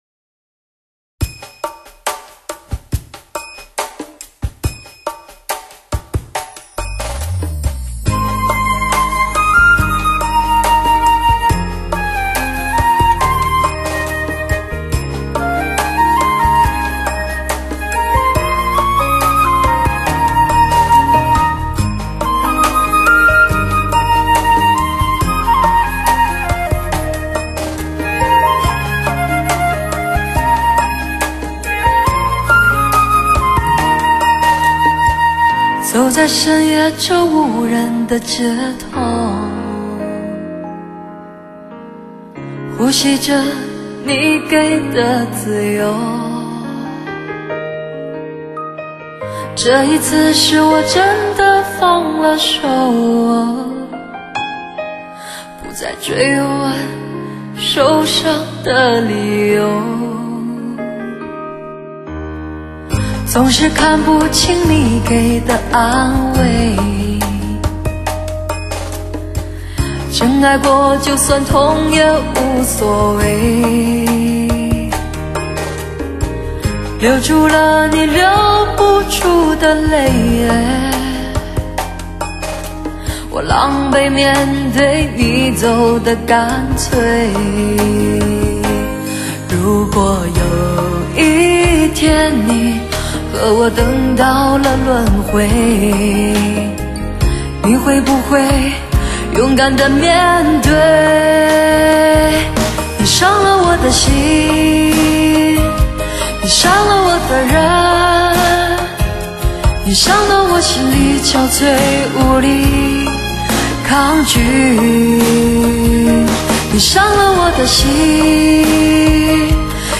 汽车HI-FI音乐发烧碟
极具穿透力的嗓音